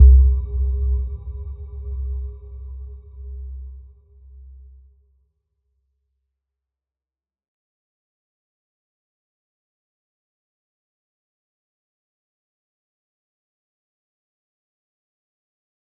Little-Pluck-C2-f.wav